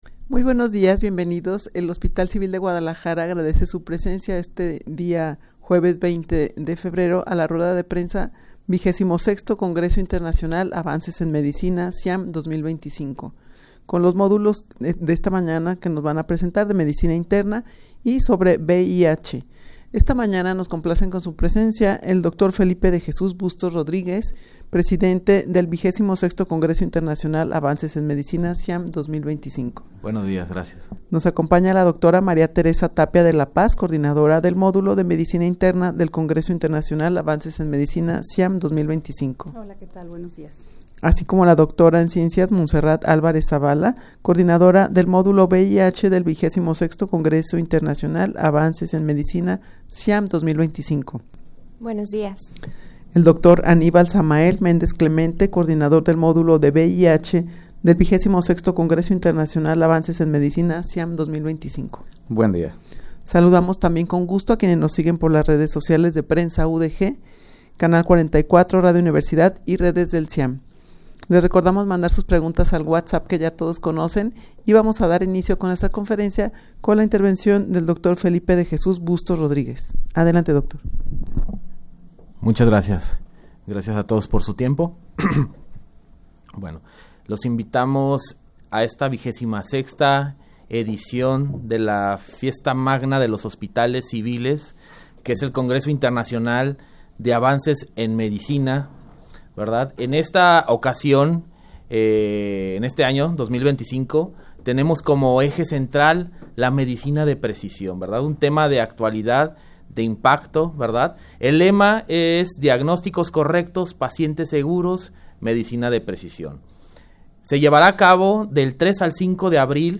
Audio de la Rueda de Prensa
rueda-de-prensa-xxvi-congreso-internacional-avances-en-medicina-ciam-2025-con-los-modulos-medicina-interna-y-vih.mp3